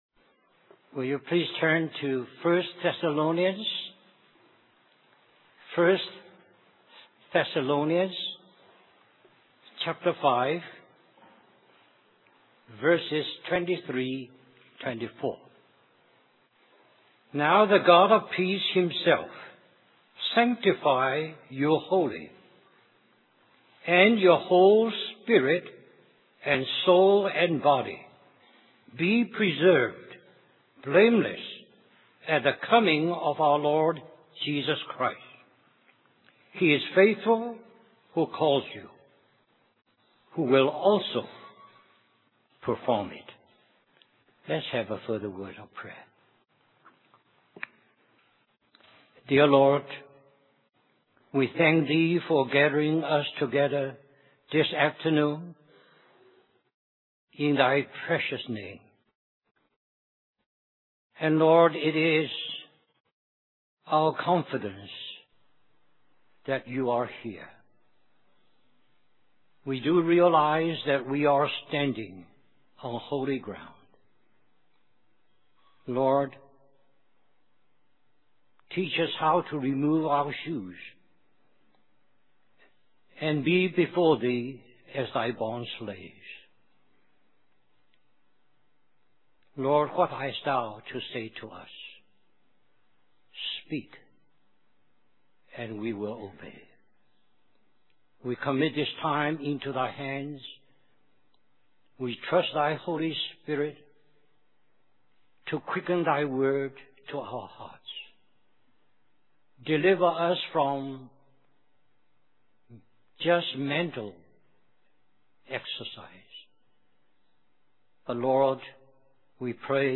Christian Family Conference
Message